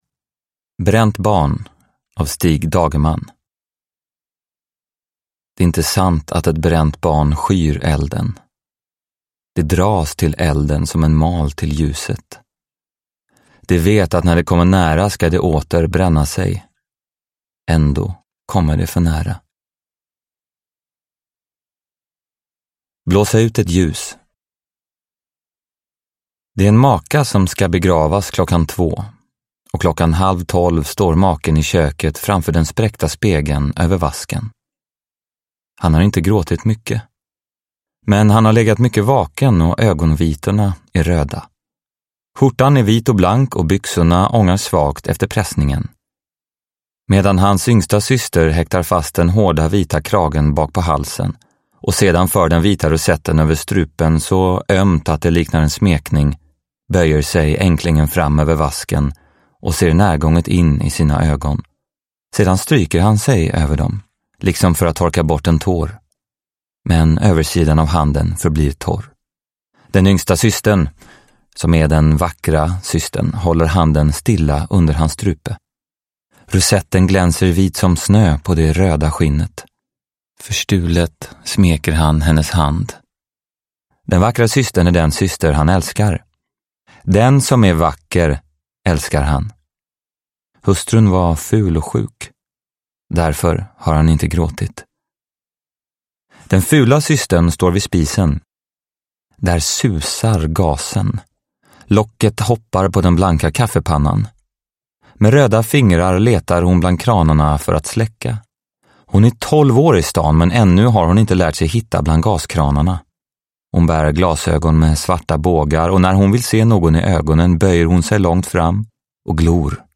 Bränt barn – Ljudbok – Laddas ner
Adam Lundgren läser den kritikerrosade klassikern.
Uppläsare: Adam Lundgren